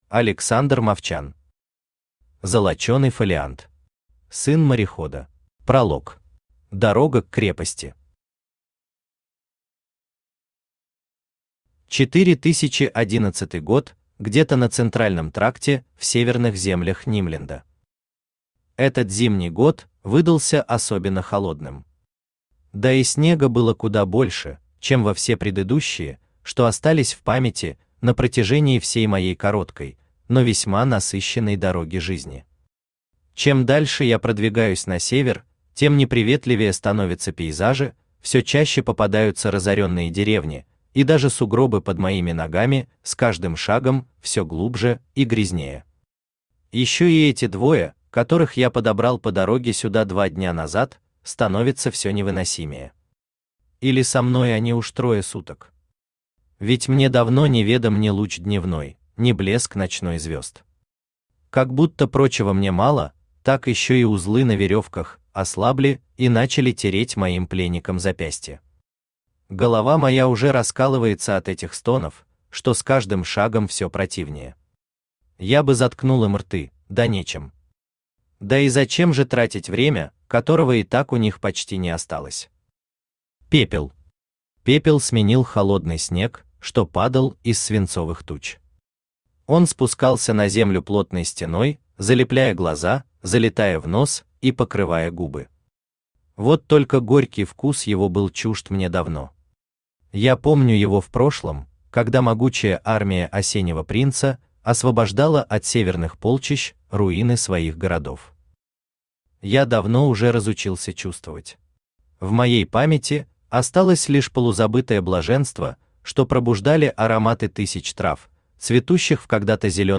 Сын морехода Автор Александр Юрьевич Мовчан Читает аудиокнигу Авточтец ЛитРес.